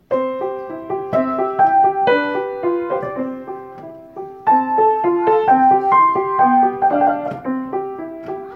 Here’s a recording of an old upright piano that hadn’t been tuned in years before tuning, and another recording of it after I had tuned it, so you can hear the difference:
After (tuned piano):
tuned-piano.mp3